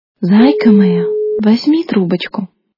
» Звуки » Люди фразы » Зайка моя! - Возьми трубочку
При прослушивании Зайка моя! - Возьми трубочку качество понижено и присутствуют гудки.